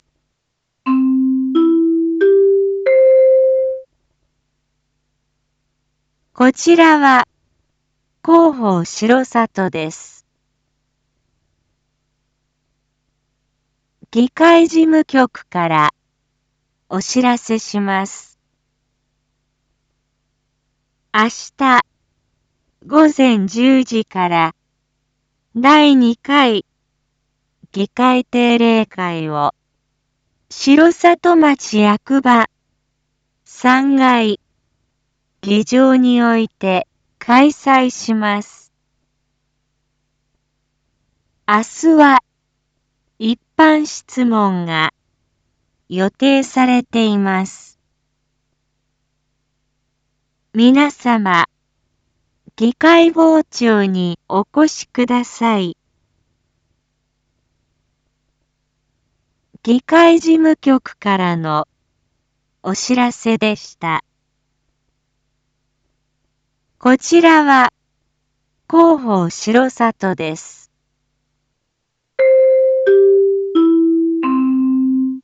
一般放送情報
Back Home 一般放送情報 音声放送 再生 一般放送情報 登録日時：2024-06-05 19:01:16 タイトル：第２回議会定例会⑤ インフォメーション：こちらは広報しろさとです。